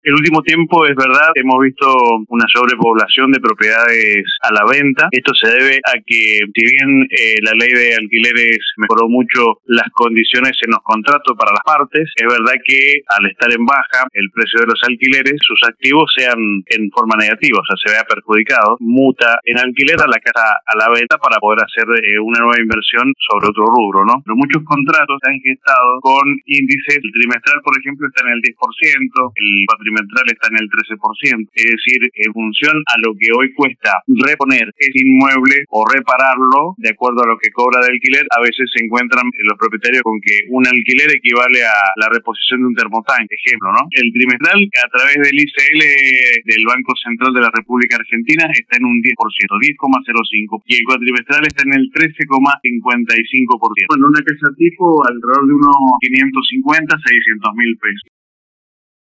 En una entrevista con LV18